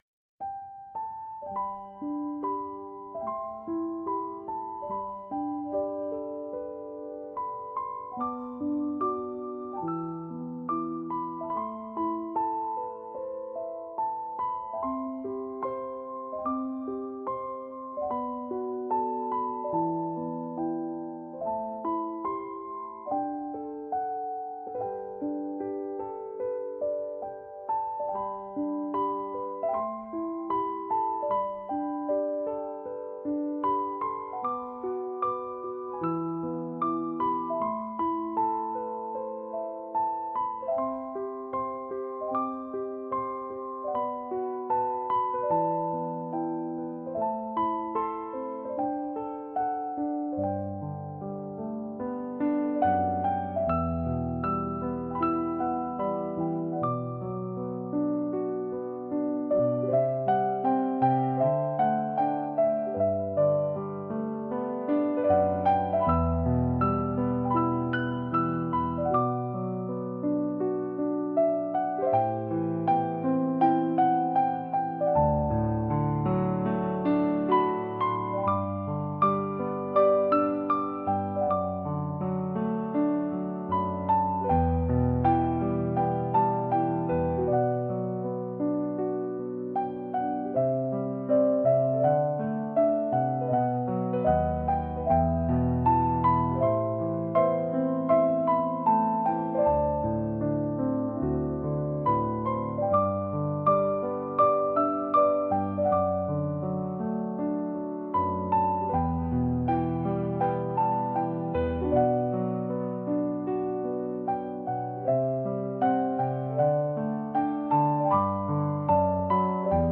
回想シーンにも使えると思います！